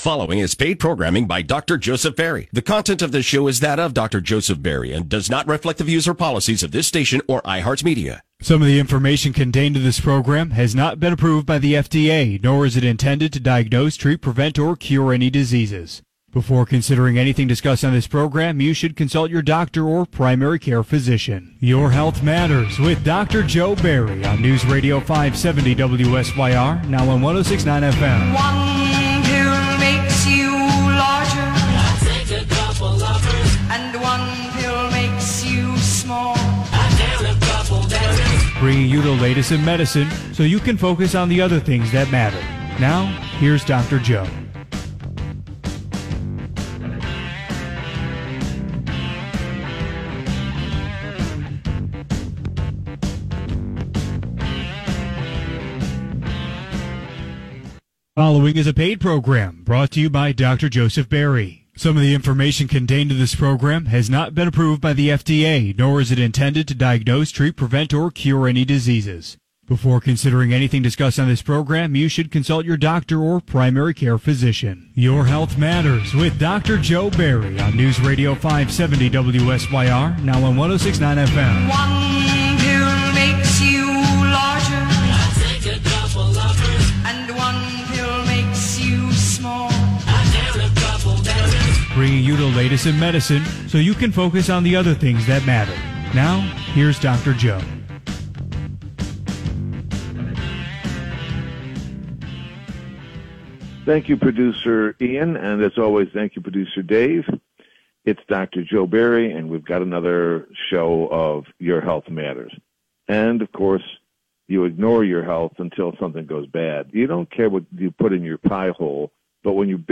Disclaimer: This radio program is produced by an individual medical practitioner and not affiliated with OA.